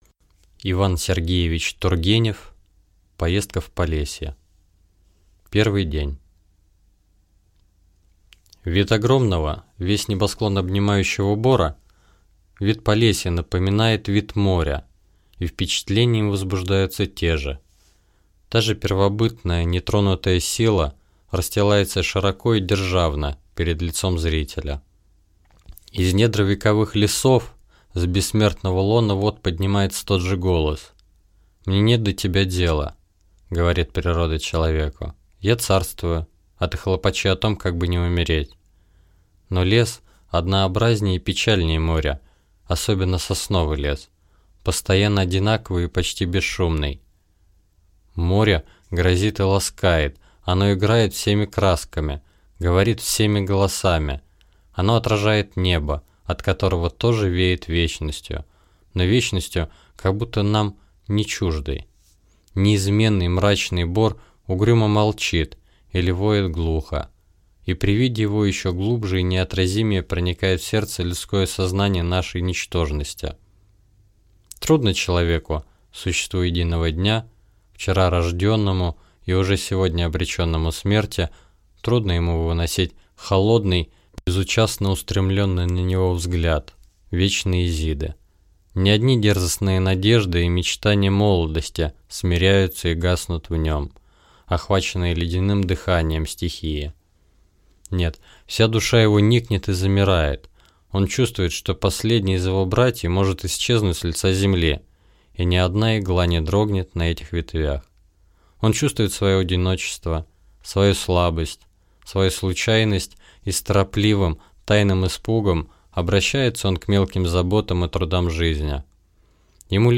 Аудиокнига Поездка в Полесье | Библиотека аудиокниг